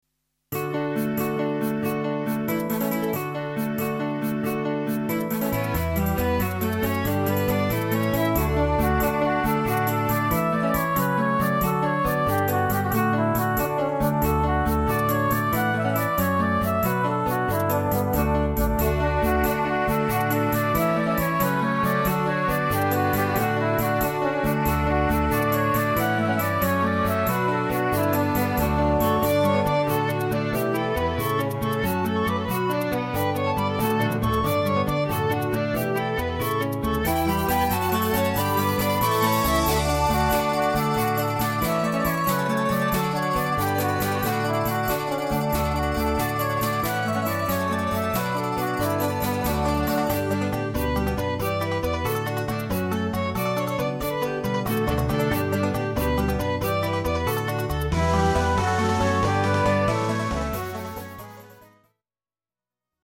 Hierbij een aantal fragmenten op basis van inheemse en folkloristische instrumenten.
Cum Laude Britse folkmuziek 1:03 988kb 1986 Introfragment uit dit stukje. Zomers en opwekkend